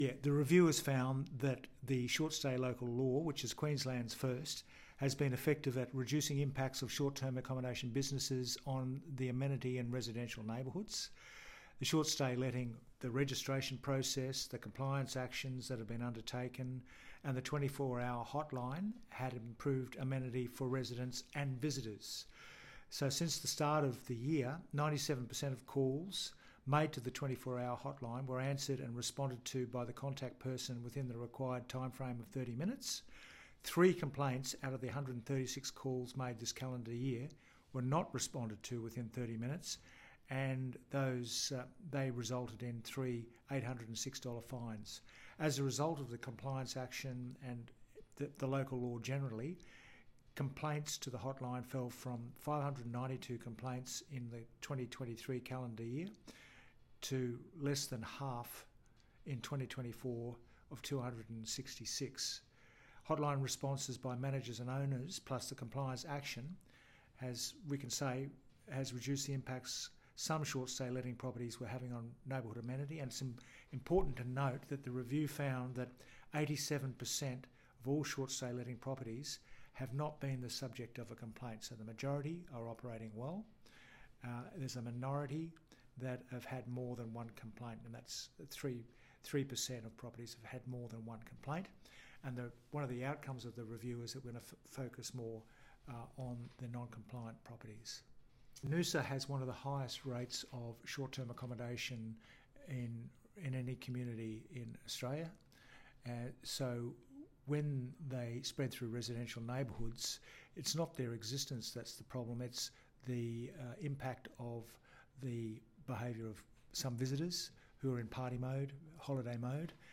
mayor-frank-wilkie-short-stay-local-law-review.mp3